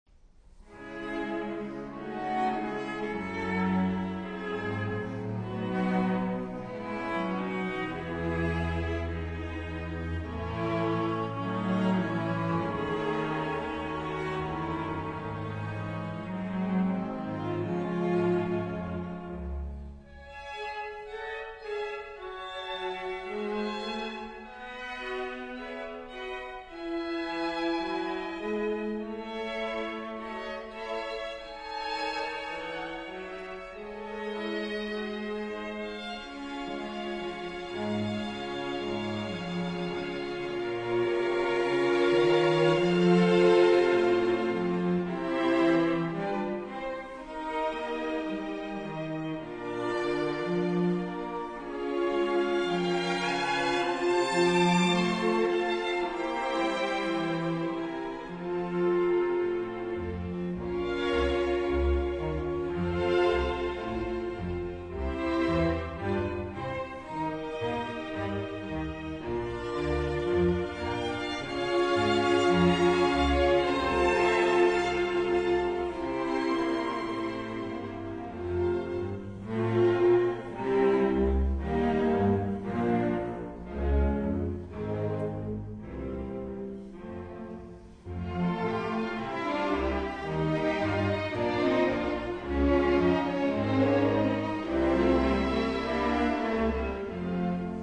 Philharmonisches Orchester des Staatstheaters Mainz
Symphonie für Streichorchester As-Dur
I. Allegro con fuoco. Agitato con brio